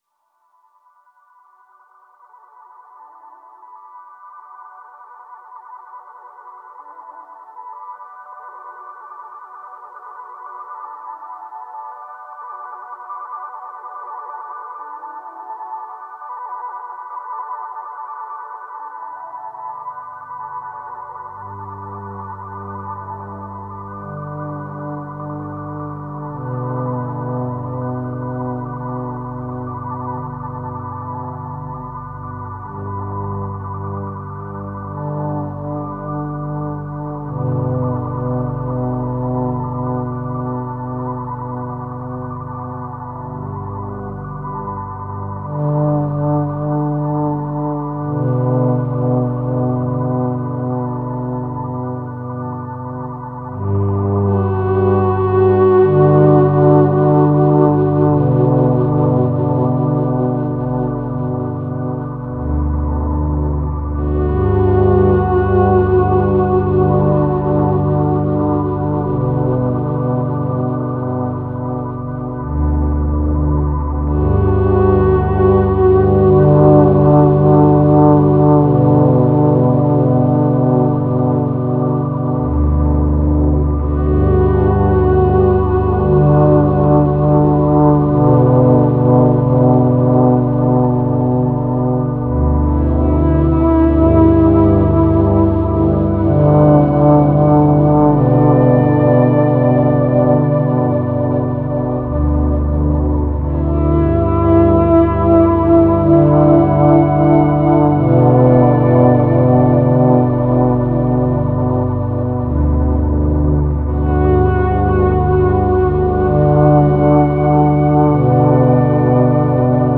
Genre: Relax, Meditation, Ambient, New Age, Ambient.